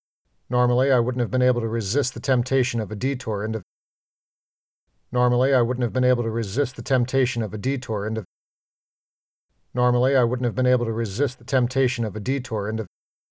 I attach a sound test from a poster who got dangerously close to producing an AudioBook compliant sound clip with no corrections short of a minor volume change.
However, there is a word “resist” at about 8.3 seconds that is a little bright. Turns out it’s bright enough that it stands in the way of meaningful equalization of the performance.